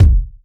• Original Urban Steel Kick Drum Sound D# Key 189.wav
Royality free kick drum tuned to the D# note. Loudest frequency: 129Hz
original-urban-steel-kick-drum-sound-d-sharp-key-189-KyG.wav